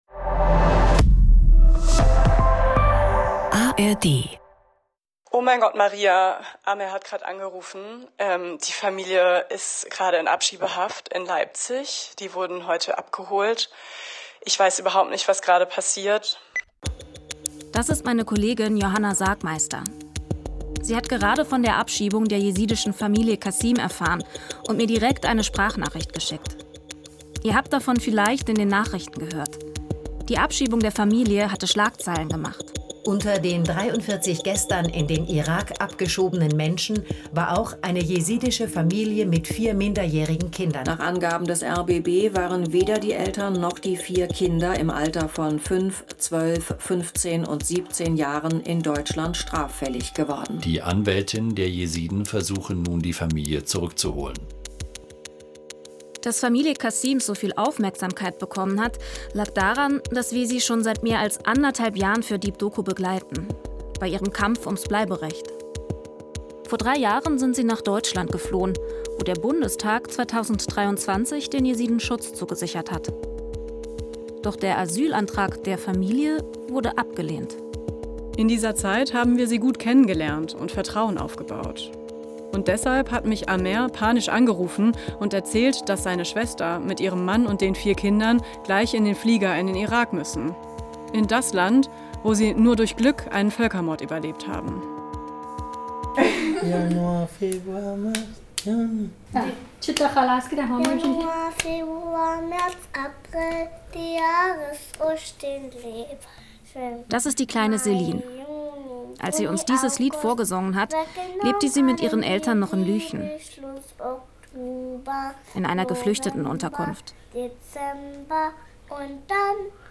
Deep Doku erzählt persönliche Geschichten und taucht alle zwei Wochen in eine andere Lebensrealität ein. Egal ob im Technoclub, der Notrufzentrale der Feuerwehr, auf einer Demo oder im Wohnzimmer – wir sind in unseren Audio-Dokus und Reportagen ganz nah dran.